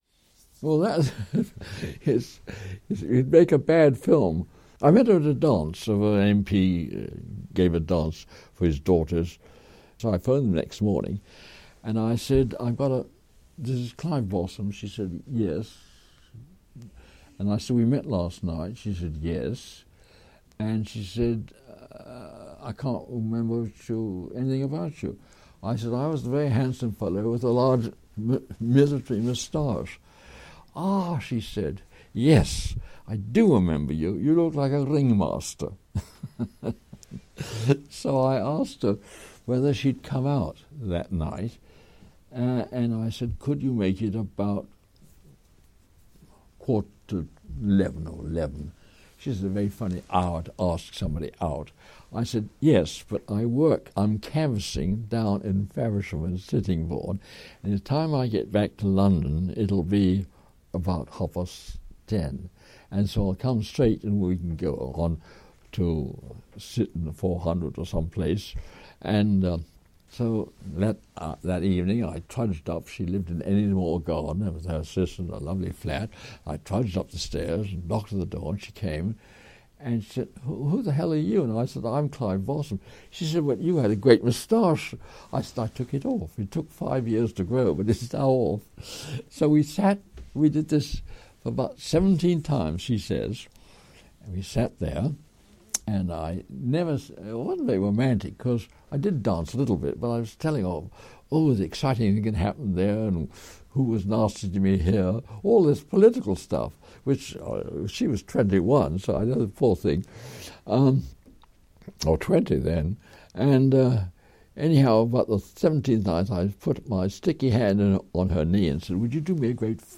Here we take a look back on our interview with him…